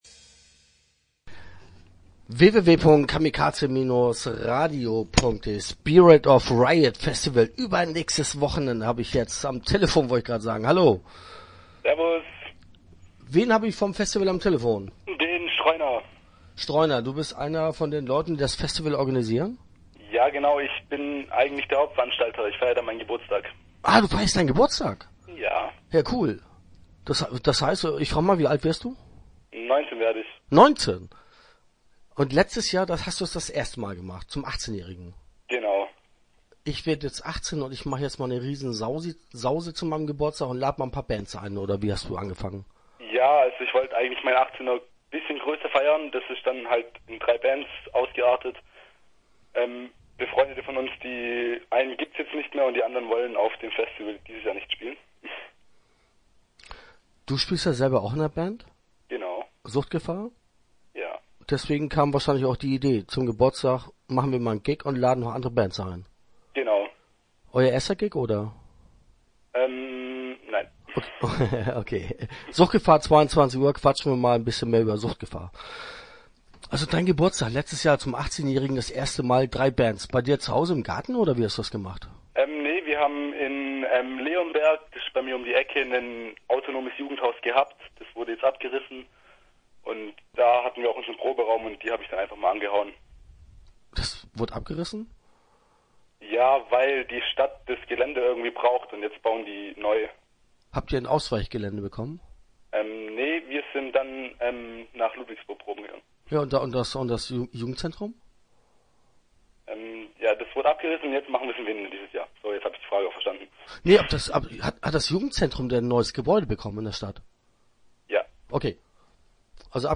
Interview Teil 1 (10:37)